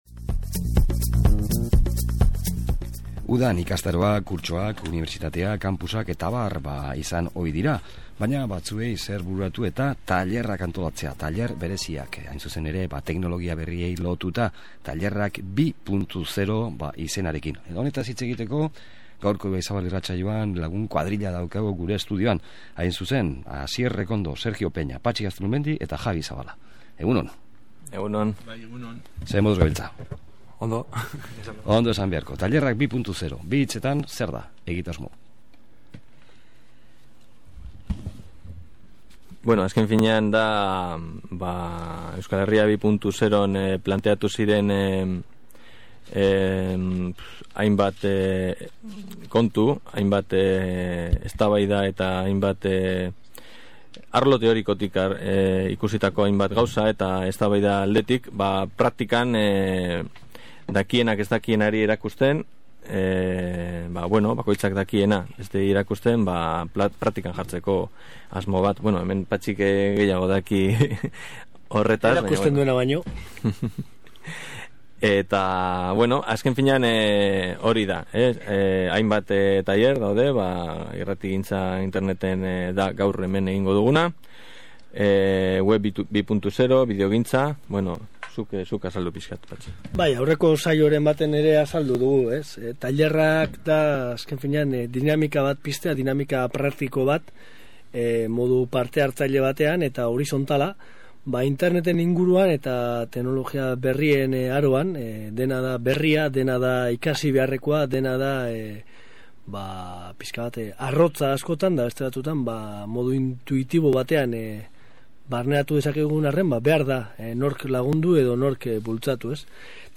Gaur Tailerrak 2.0 ekimeneko lagunekin izan gara Bilbo Hiria irratian. Asteotan Euskal Herriko hainbat lekutan egingo diren lantegi praktikoak dira hauek, iazko irailean egin ziren EH 2.0 jardunaldien jarraipena.